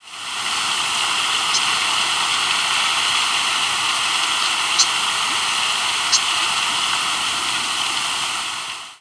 Common Yellowthroat diurnal flight calls
Diurnal calling sequences: